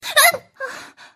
女生叫.mp3